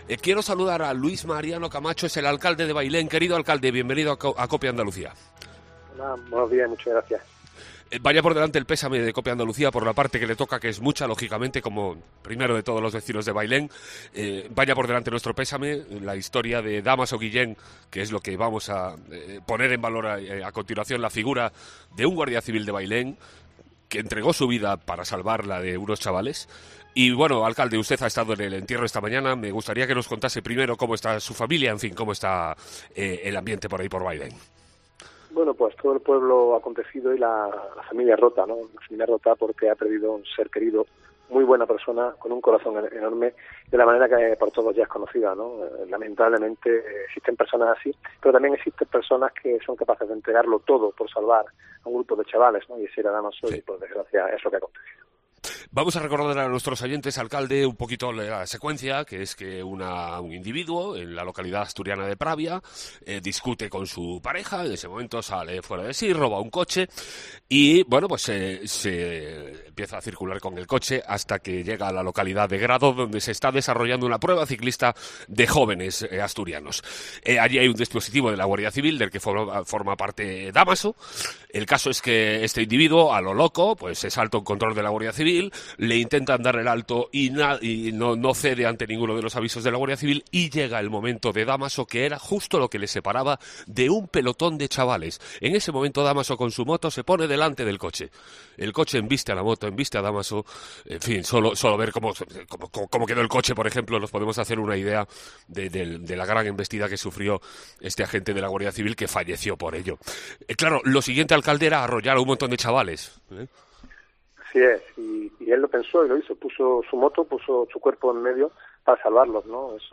ENTREVISTA CON EL ALCALDE DE BAILÉN EN COPE ANDALUCÍA